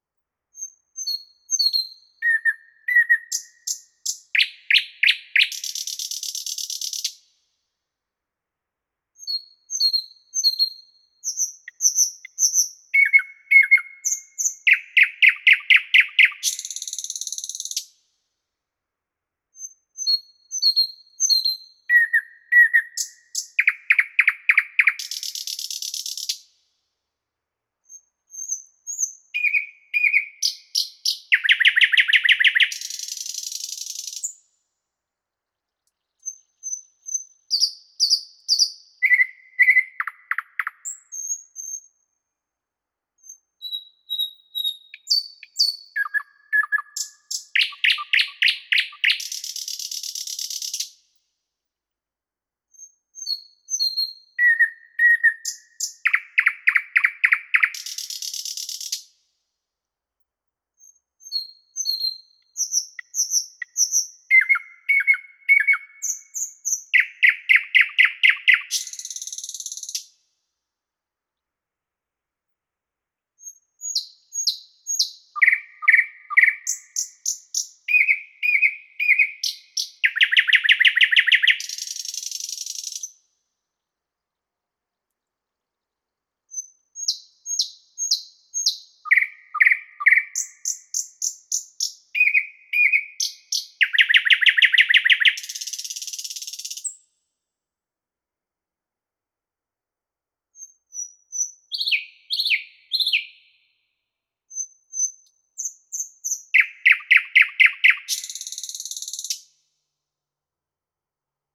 Wer sich fragt wie singt die Nachtigall, entdeckt schnell: Ihr Gesang besteht aus vielfältigen, klaren und lauten Strophen, die aus Pfeif-, Triller- und Knacklauten bestehen.
Besonders auffällig ist der plötzliche Wechsel von melodischen Passagen zu schnellen Tonfolgen, der im Vogelreich selten ist.
Der Nachtigall Gesang
Der-Nachtigall-Gesang-Voegel-in-Europa.wav